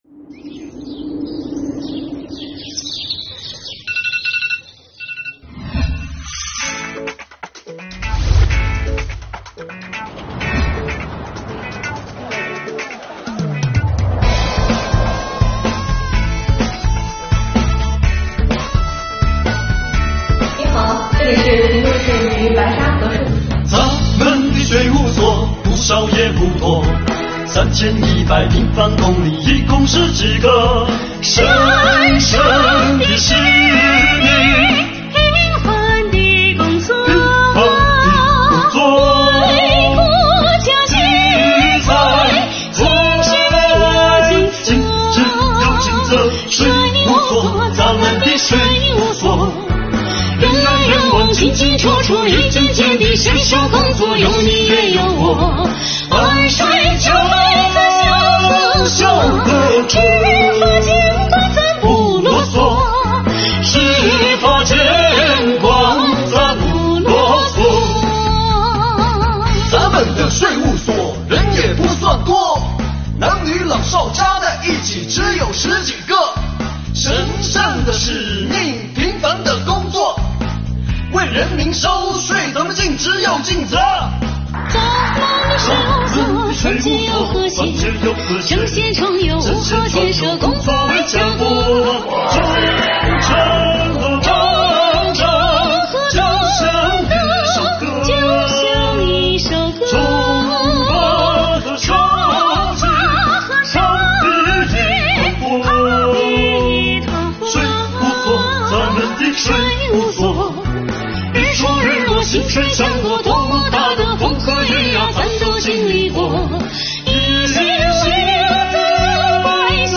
作品是一首原创MV，节奏轻快、主题鲜明、视觉冲击力强，旋律与歌词朗朗上口，富有张力。